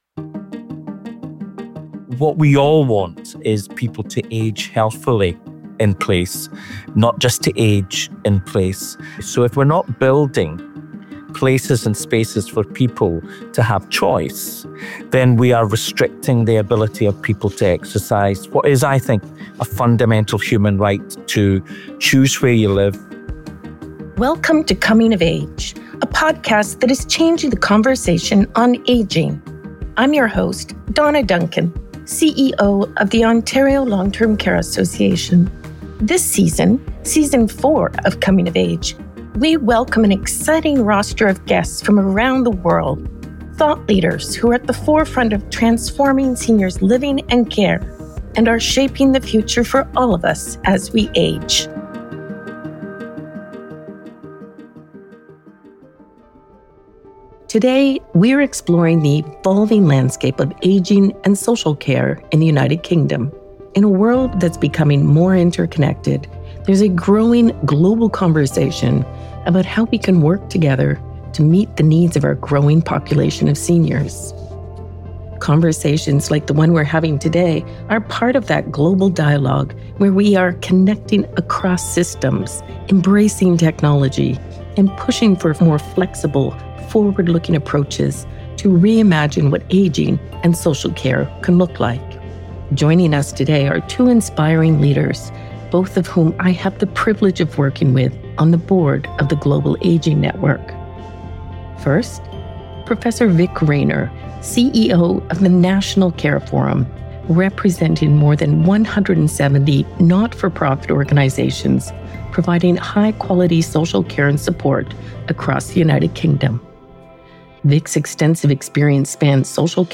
in lively discussions with national and international experts on ageism and how to reimagine seniors’ care.